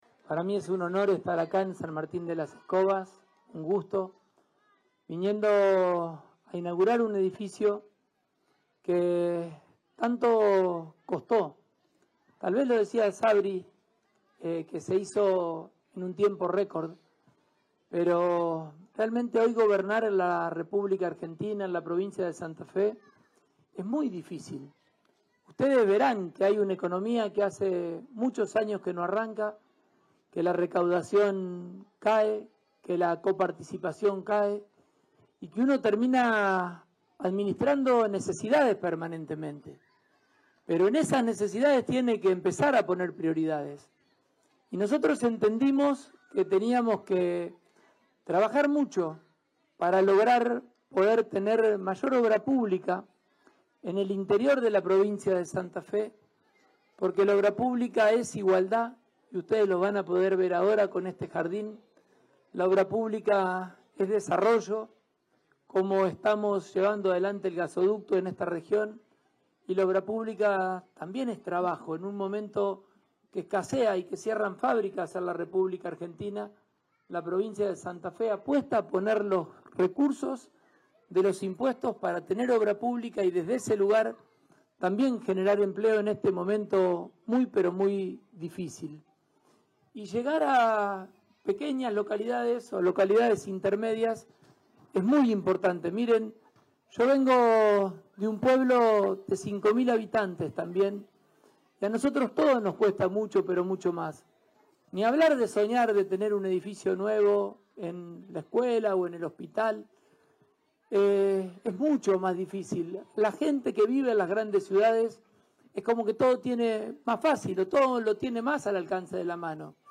Inauguración de un jardín de infantes en San Martín de las Escobas